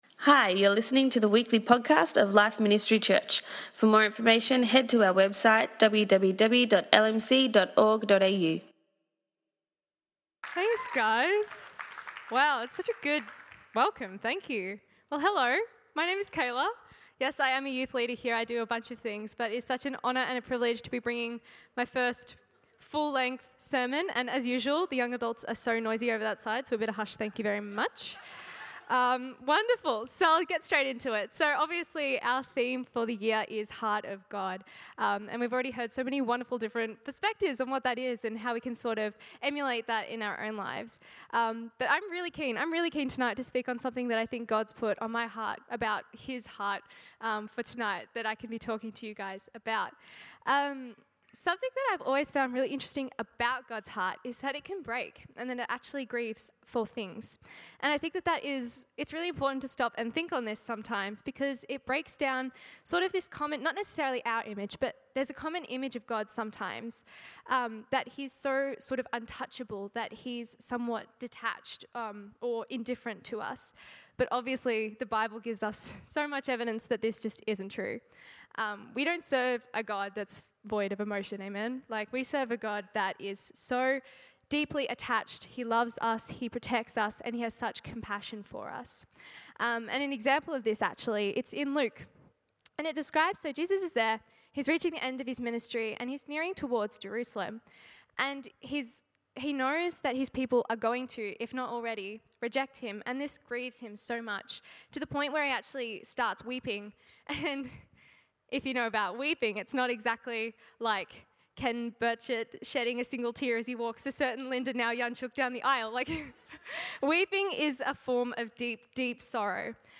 Tonight we heard a message